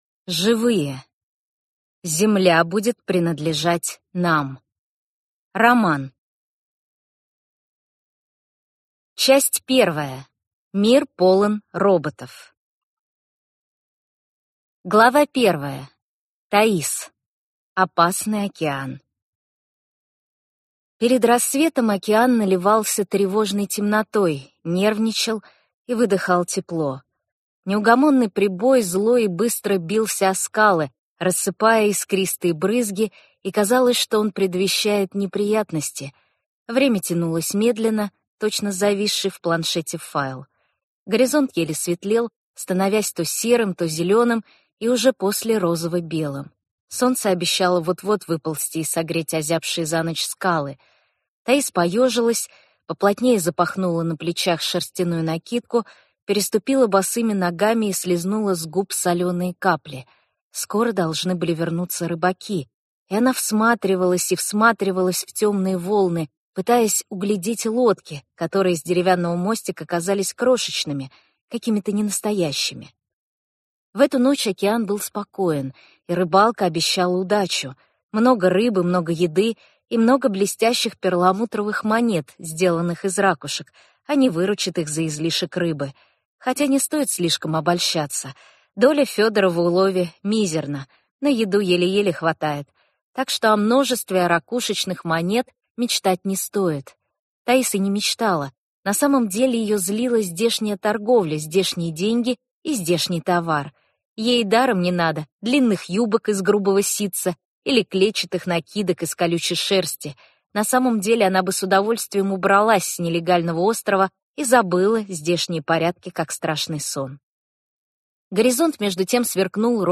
Аудиокнига Земля будет принадлежать нам | Библиотека аудиокниг